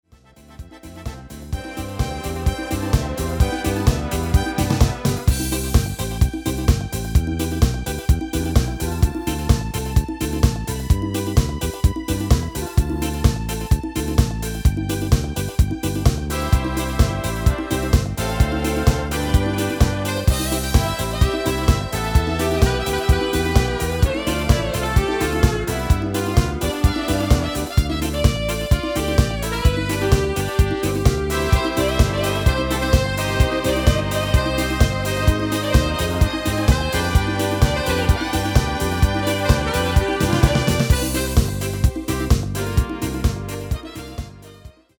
Demo/Koop midifile
Genre: Duitse Schlager
Toonsoort: C
- Vocal harmony tracks